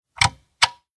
Tischtelefon Fg tist 264 b